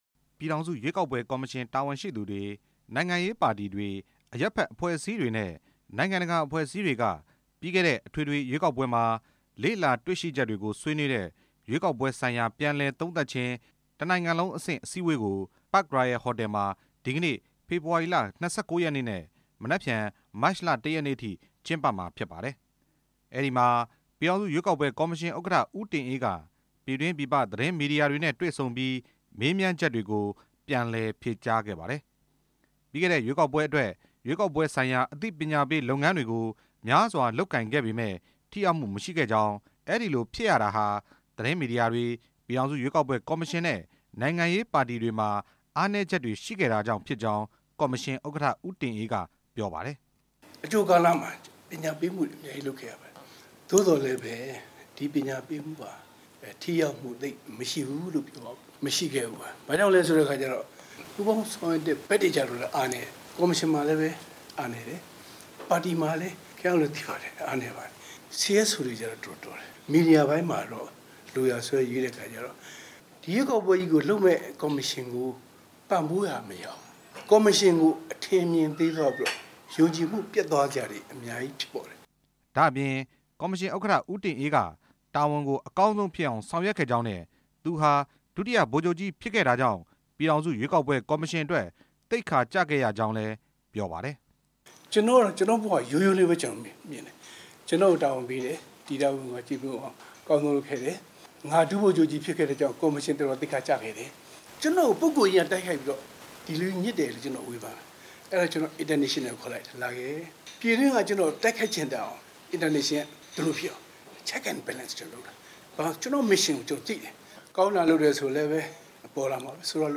ပြီးခဲ့တဲ့ နိုဝင်ဘာလ ၈ ရက်နေ့က ကျင်းပခဲ့တဲ့ အထွေထွေရွေးကောက်ပွဲကို ပြန်လည်သုံးသပ်တဲ့ အစည်းအဝေးကို ရန်ကုန်တိုင်းဒေသကြီး၊ ဒဂုံမြို့နယ်က Park Royal ဟိုတယ်မှာ ကျင်းပနေပါတယ်။
အဲ့ဒီအစည်းအဝေးမှာ ပြည်ထောင်စု ရွေးကောက်ပွဲကော်မရှင် ဥက္ကဋ္ဌ ဦးတင်အေးက ပြည်တွင်း၊ ပြည်ပ သတင်းထောက်တွေနဲ့ သီးသန့်တွေ့ဆုံပြီး မေးမြန်းချက်တွေကို ပြန်လည်ဖြေကြားခဲ့ပါတယ်။